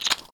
bite-small2.wav